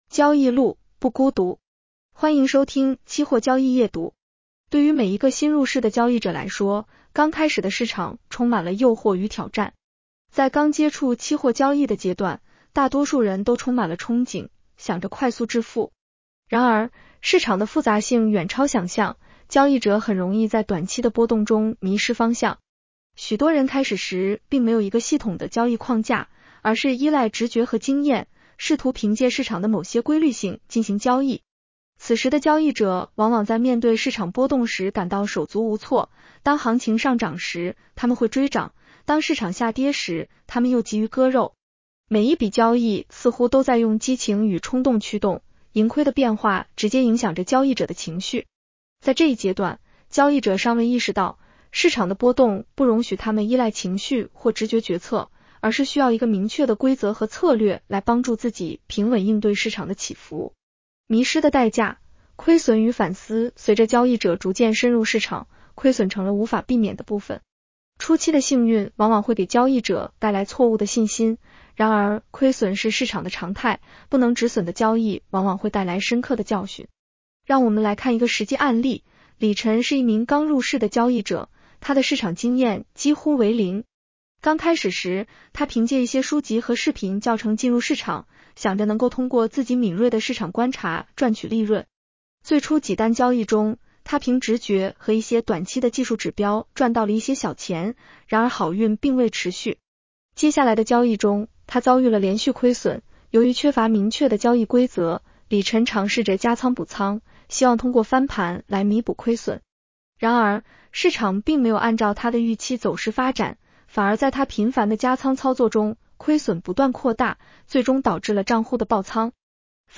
女声普通话版 下载mp3 交易路，不孤独。